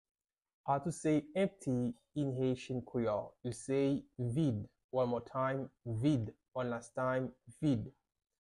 How to say "Empty" in Haitian Creole - "Vid" pronunciation by a native Haitian tutor
“Vid” Pronunciation in Haitian Creole by a native Haitian can be heard in the audio here or in the video below:
How-to-say-Empty-in-Haitian-Creole-Vid-pronunciation-by-a-native-Haitian-tutor.mp3